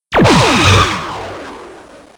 plasma.ogg